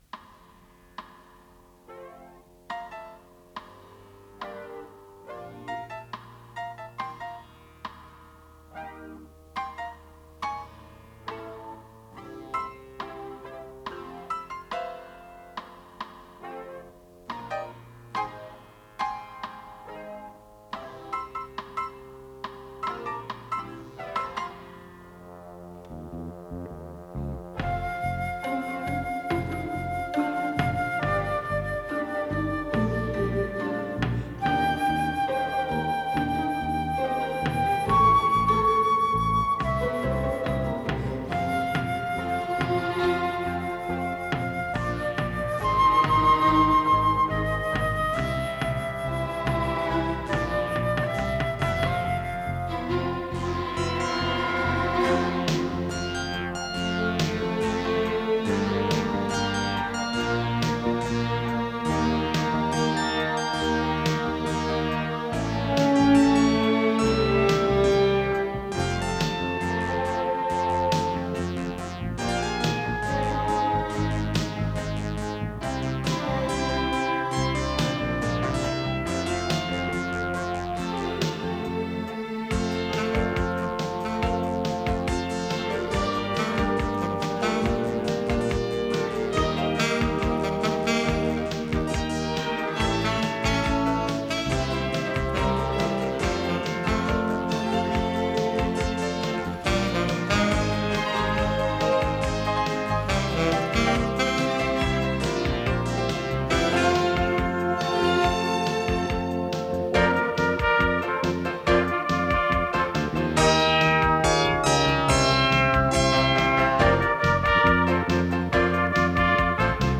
Скорость ленты38 см/с
ВариантДубль моно